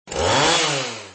chainsaw_1s.mp3